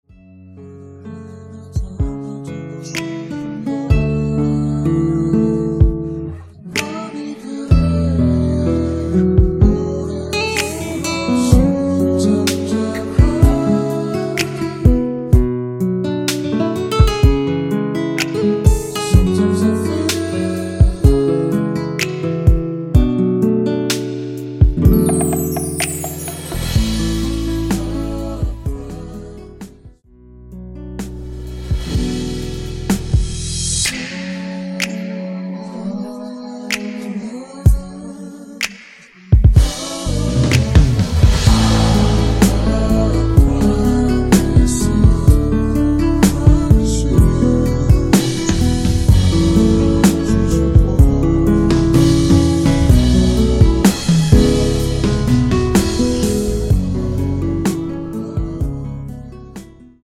원키에서(-3)내린 코러스 포함된 MR 입니다.(미리듣기 참조)
앞부분30초, 뒷부분30초씩 편집해서 올려 드리고 있습니다.
중간에 음이 끈어지고 다시 나오는 이유는